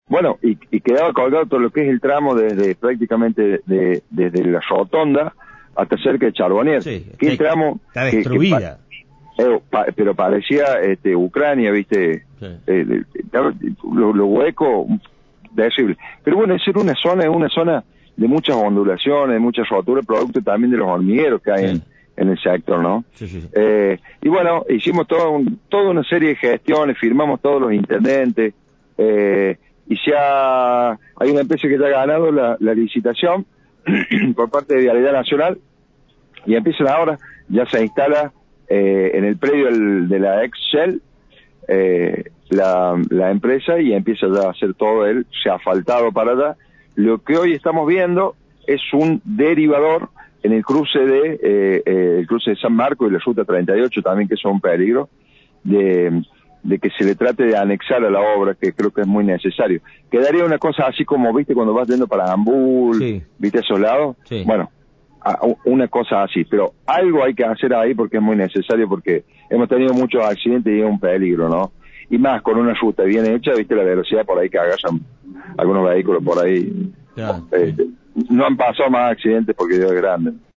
Farías en dialogo con Cadena Centro FM brindó detalles sobre esta obra a pocos días de comenzar.